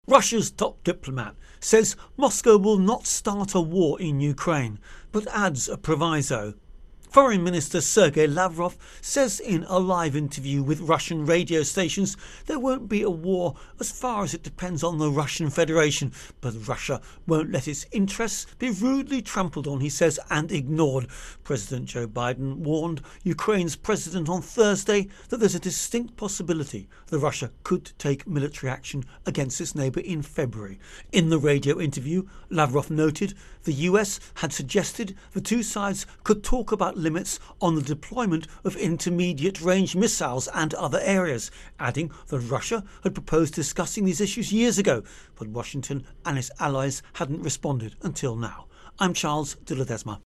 Russia-Ukraine-Tensions Intro and Voicer